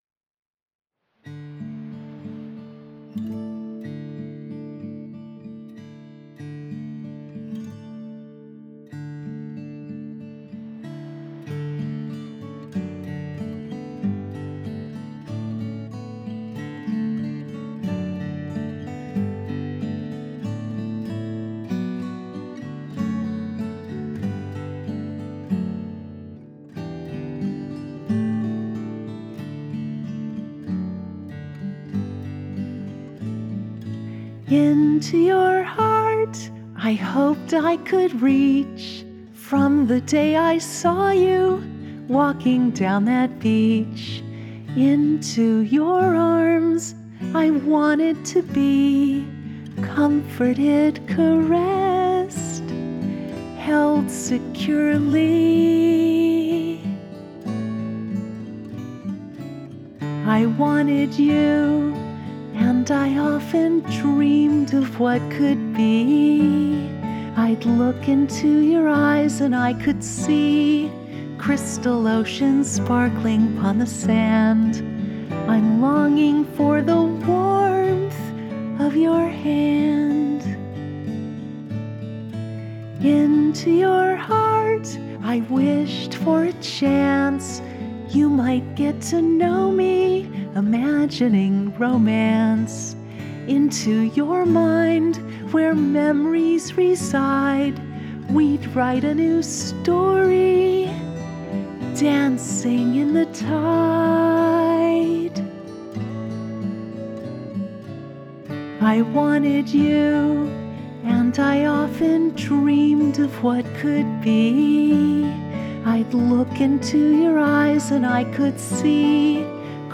Acoustic
Guitar & Piano
CRYSTAL OCEANS INSTRUMENTAL